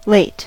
late: Wikimedia Commons US English Pronunciations
En-us-late.WAV